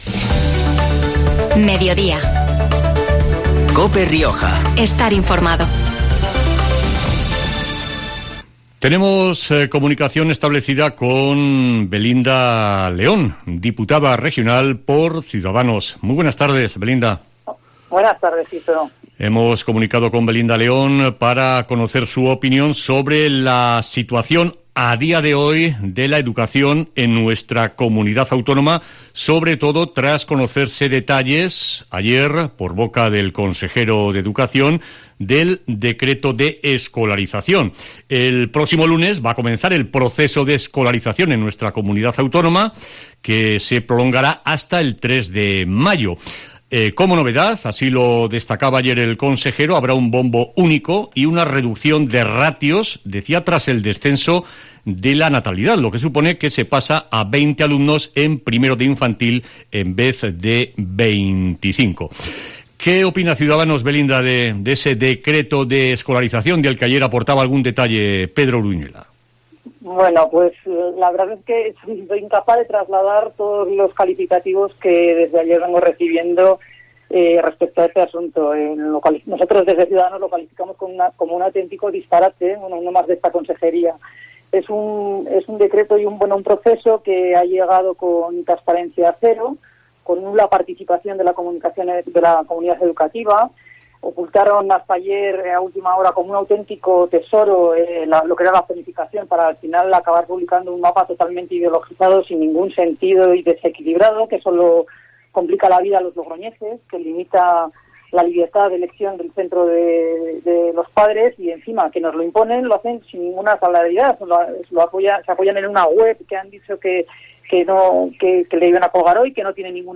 Belinda León, diputada de Ciudadanos, en COPE Rioja
La diputada de Ciudadanos La Rioja, Belinda León, ha pasado hoy por "Mediodía COPE Rioja" para hablar de la situación de la educación en La Rioja, y más concretamente, del Decreto de escolarización y de la nueva zonificación de Logroño que acaba de hacer públicos la Consejería de Educación del Gobierno regional.